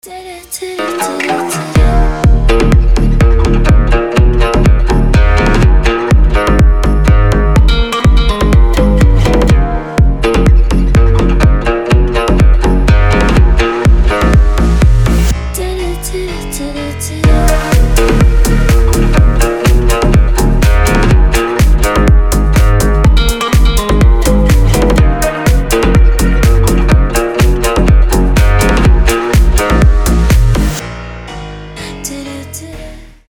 • Качество: 320, Stereo
гитара
deep house
пианино
восточные
Классный восточный мотив